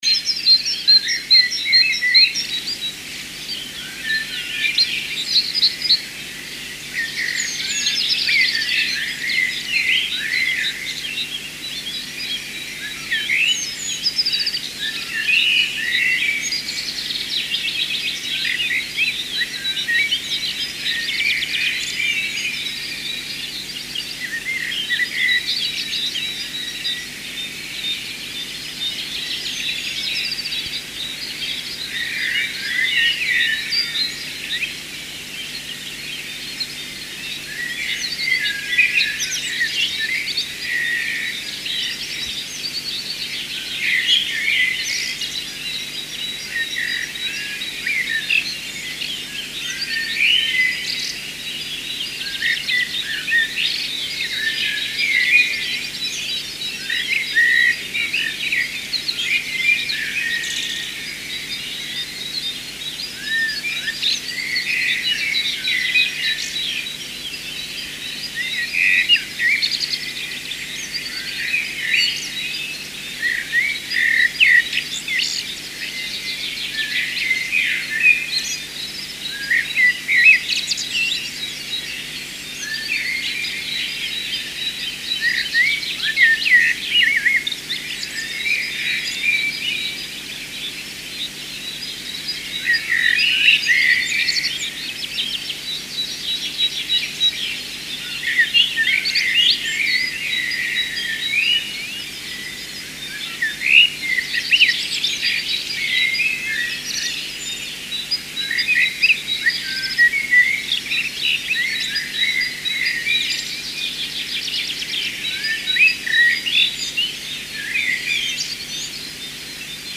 The birds outside my window on Monday morning, 5:30 am.